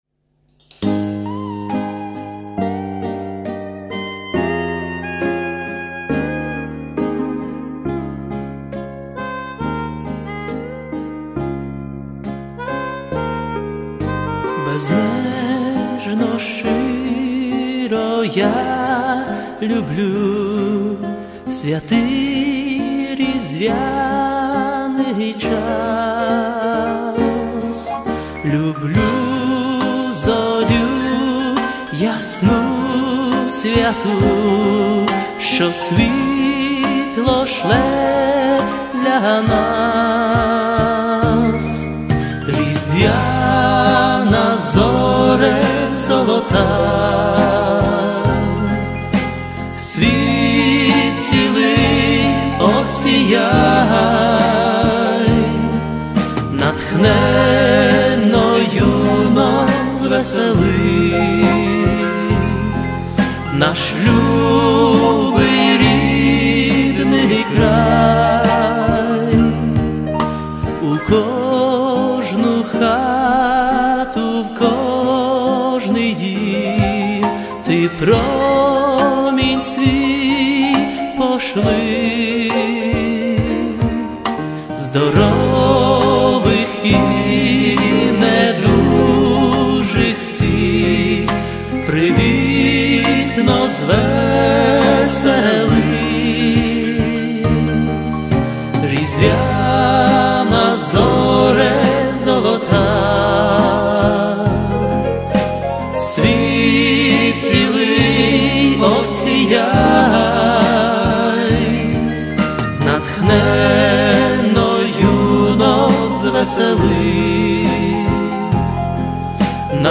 341 просмотр 104 прослушивания 0 скачиваний BPM: 68